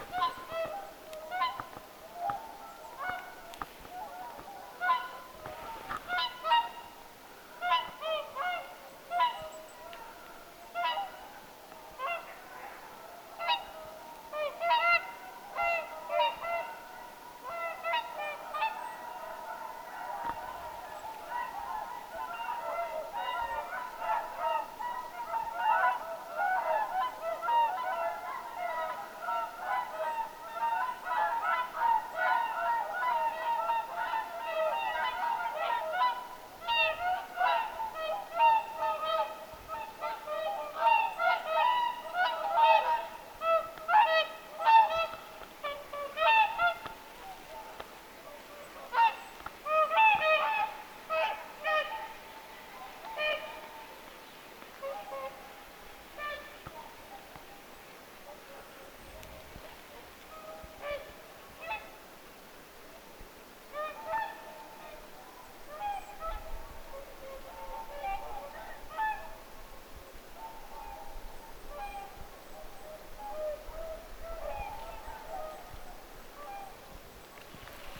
muuttoparvi lentää aika läheltä
laulujoutsenten_muuttoparvi_lentaa_ylitse_aannellen.mp3